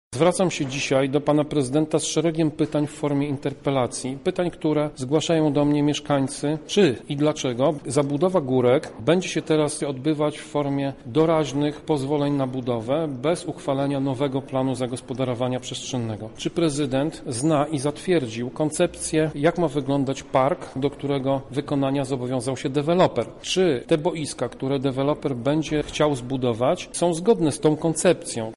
O tym, jakie pytania nasuwają się w tej sprawie, mówi sam interpelant radny Tomasz Pitucha: